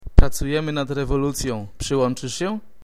schnelle Antwort und hier ist die etwas